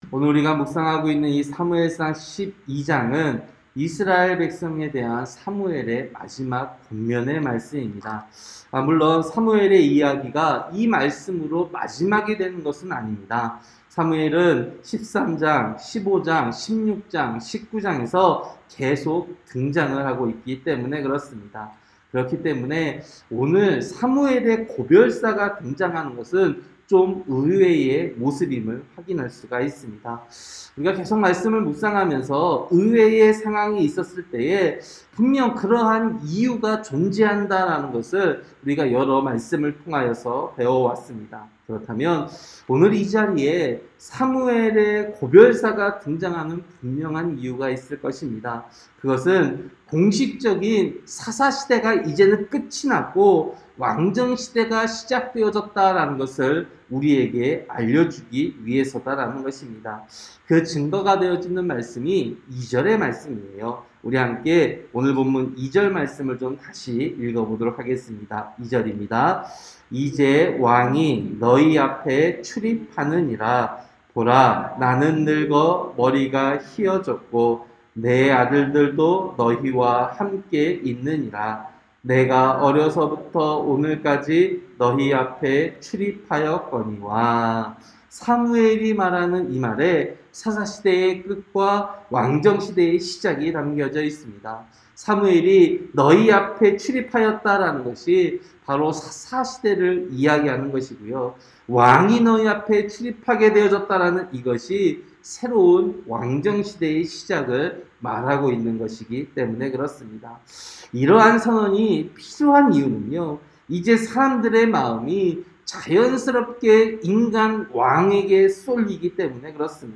새벽설교-사무엘상 12장